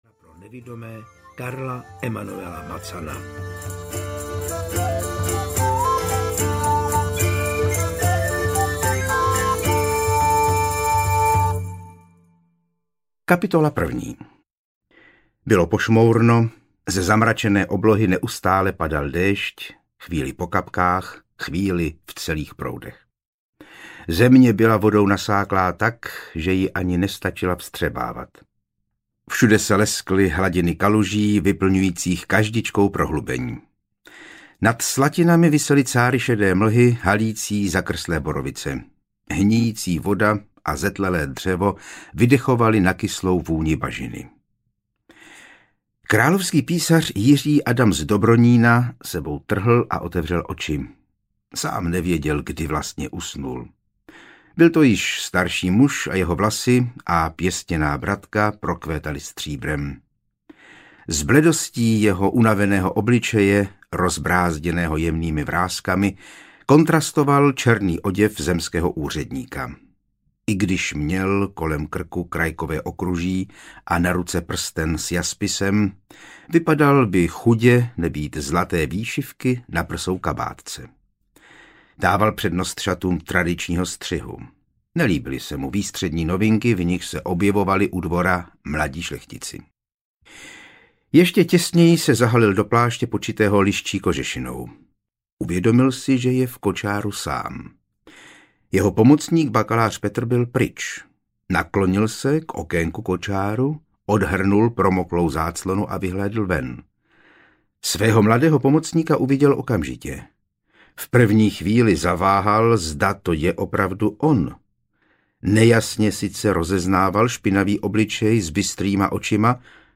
Ukázka z knihy
falesny-tolar-audiokniha